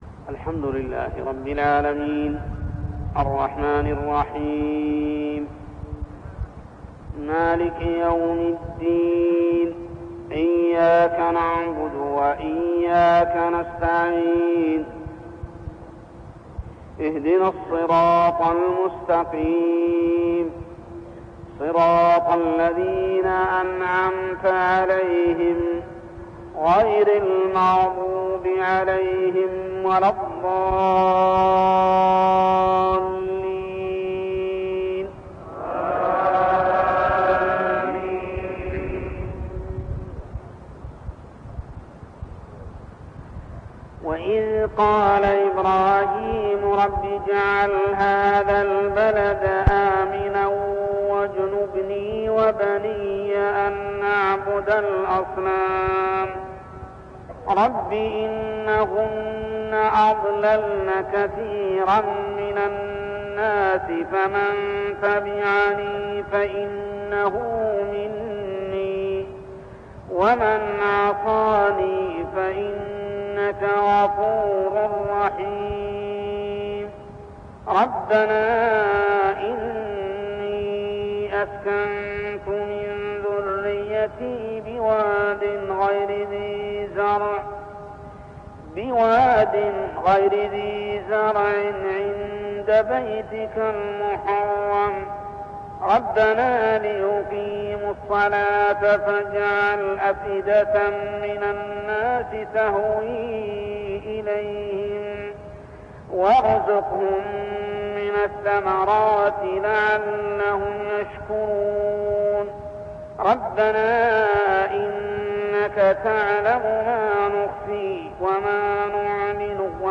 تلاوة من صلاة الفجر لخواتيم سورة إبراهيم 35-52 عام 1401هـ | Fajr prayer Surah Ibrahim > 1401 🕋 > الفروض - تلاوات الحرمين